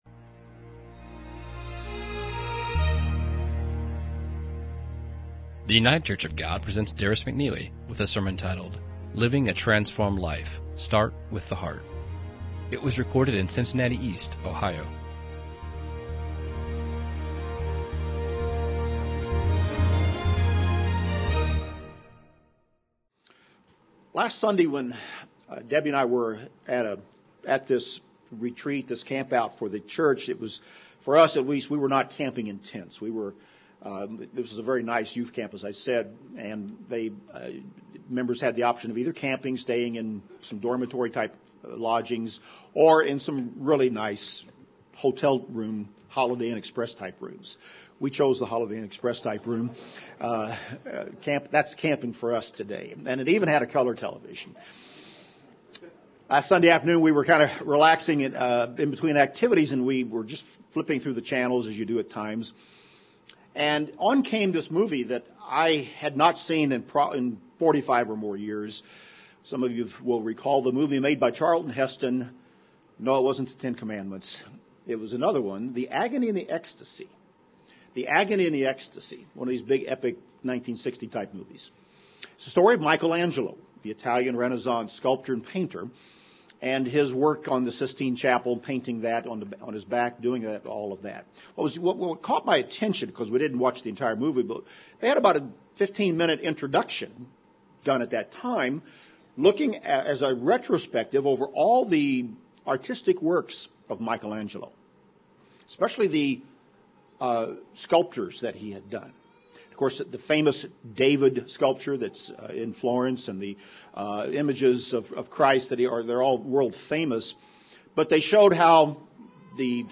This sermon looks at how to live a transformed life and how we must start by changing our heart. Is your heart bearing good spiritual fruit as a result of your contact with the word of God and the life of Jesus Christ?